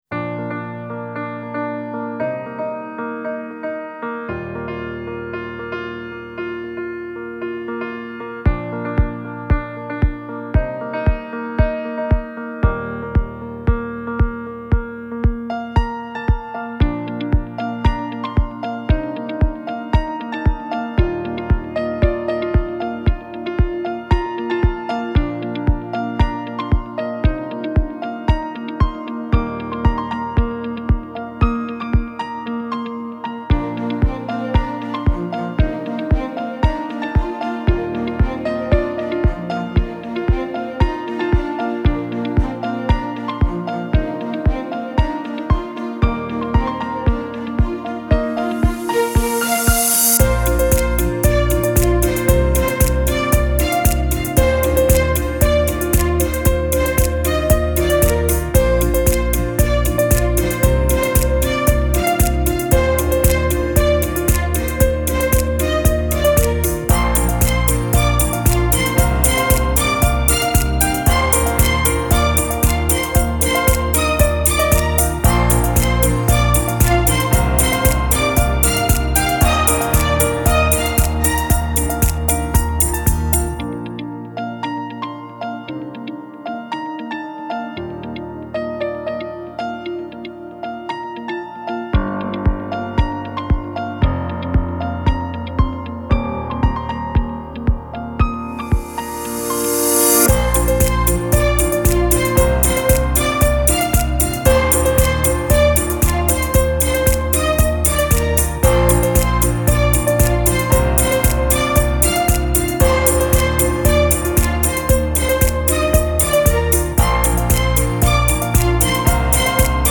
Upbeat-Piano.mp3